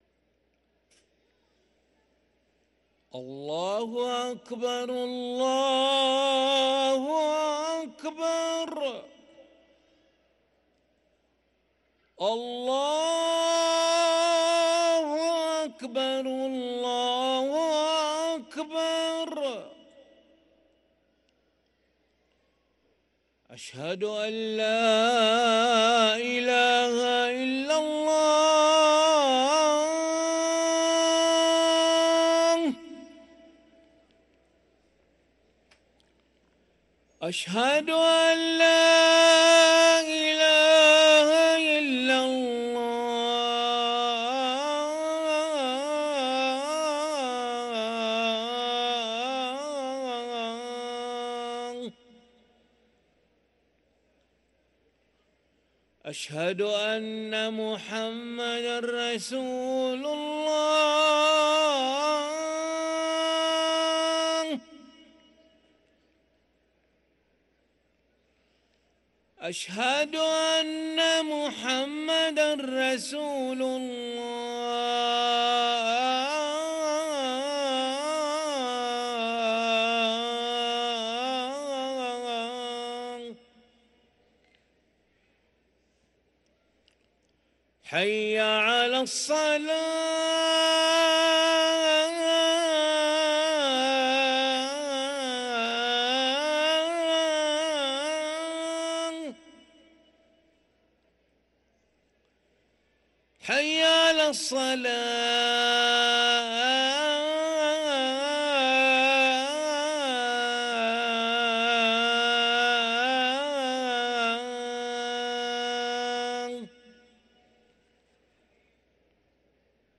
أذان العشاء للمؤذن علي ملا الأحد 14 رجب 1444هـ > ١٤٤٤ 🕋 > ركن الأذان 🕋 > المزيد - تلاوات الحرمين